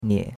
nie3.mp3